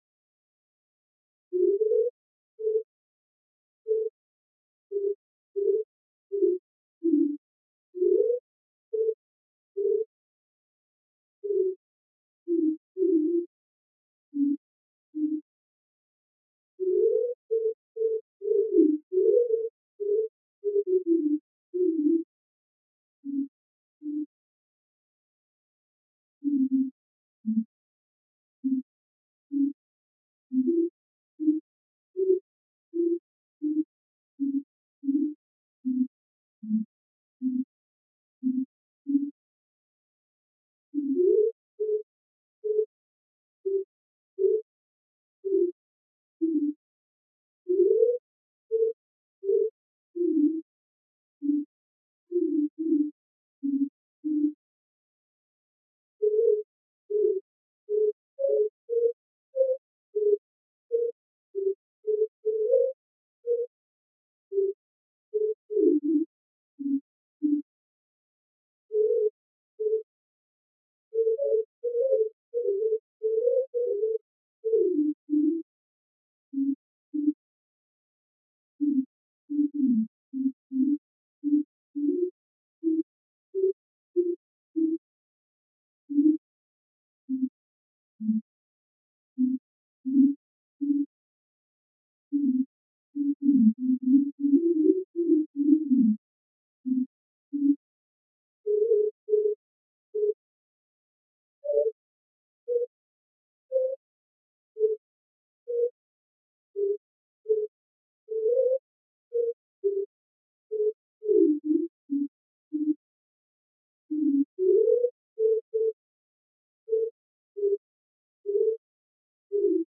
5.1—Neumatic musical notation often takes a form that graphs time versus frequency to a greater or lesser extent.
I have educed one complete kyrie, "Clemens rector aeterne," beginning on page 23 and concluding on page 25.